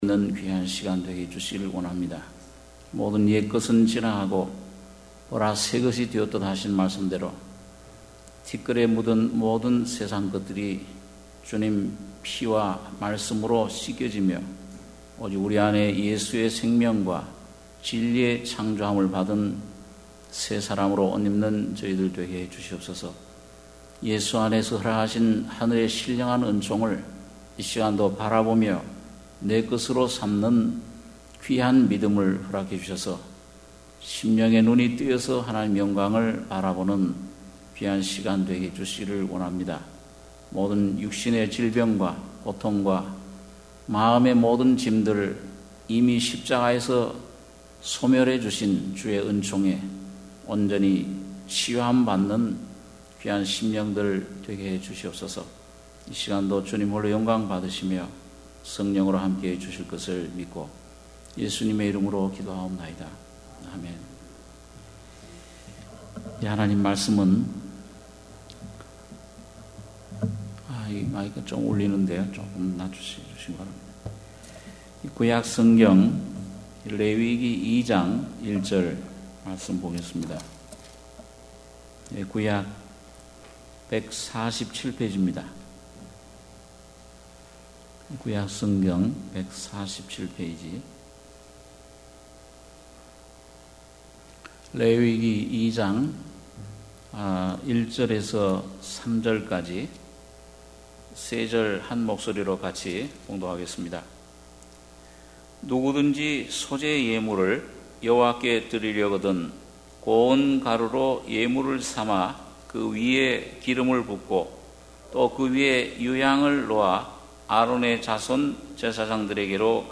수요예배 - 레위기 2장 1-3절